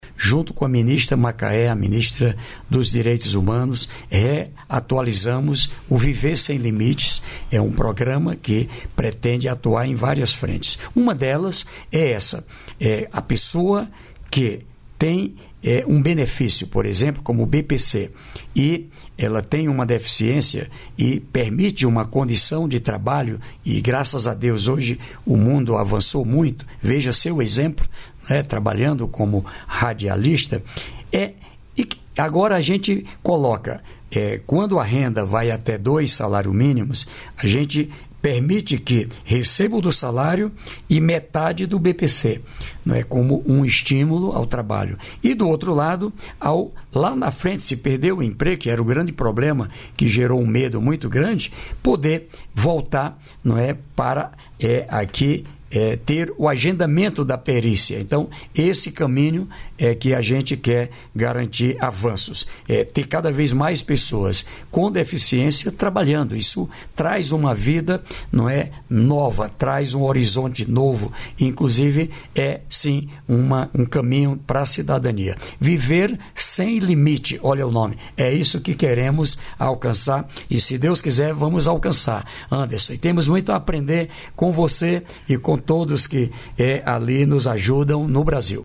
Trecho da participação ministro do Desenvolvimento e Assistência Social, Família e Combate à Fome, Wellington Dias, no programa "Bom Dia, Ministro" desta quarta-feira (07), nos estúdios da EBC em Brasília (DF).